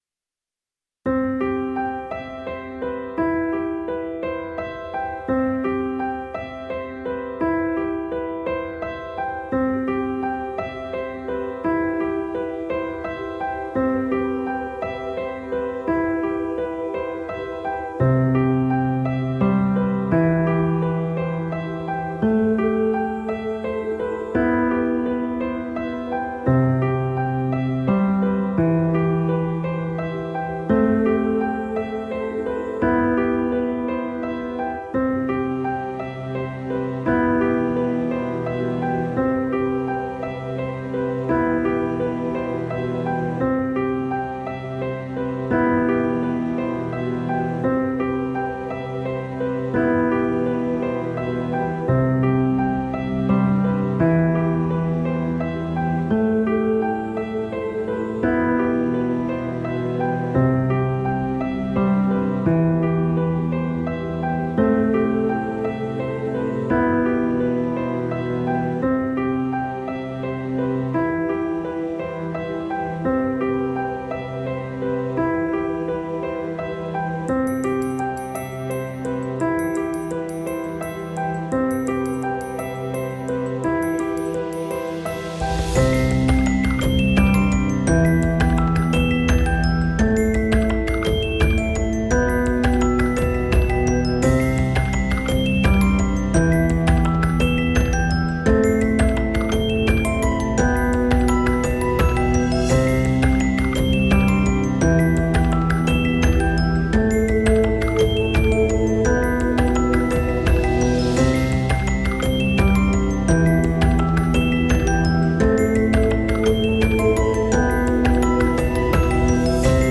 All the songs were composed in a cinematic mood.